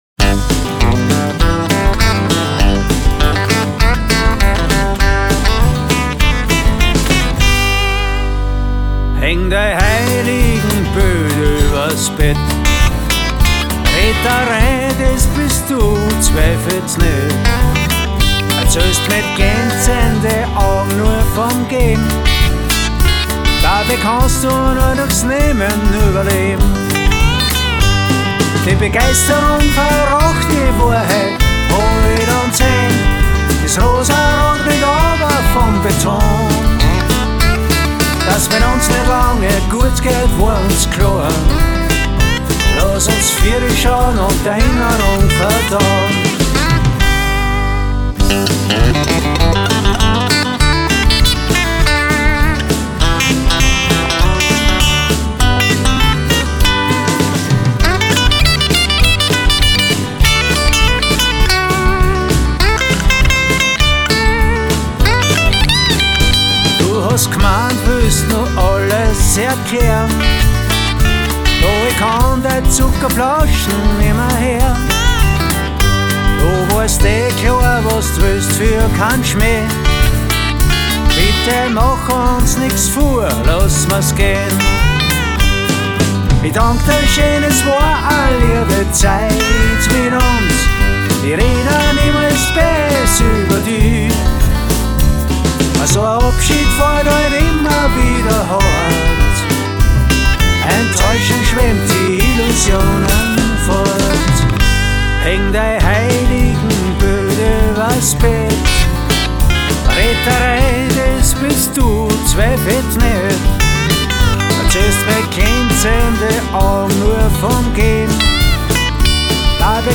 dr,b